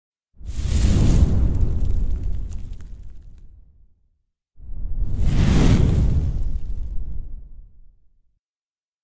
Download Fire sound effect for free.
Fire